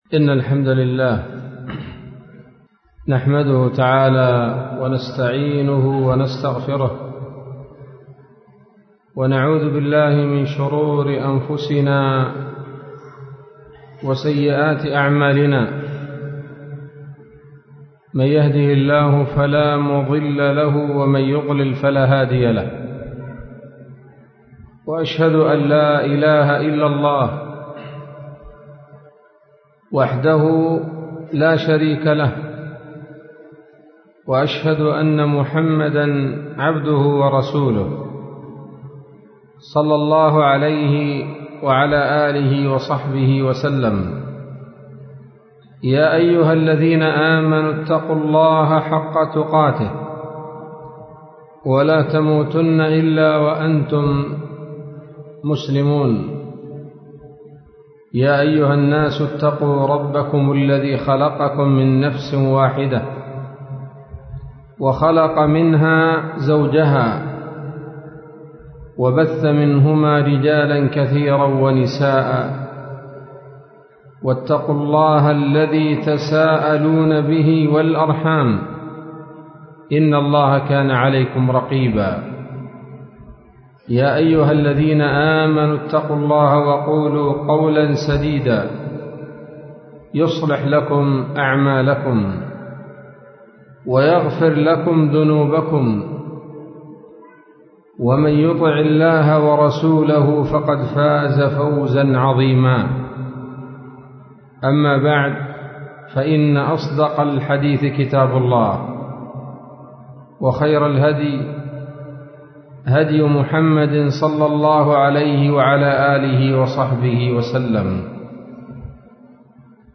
محاضرة بعنوان: (( الحصن الحصين في تربية البنين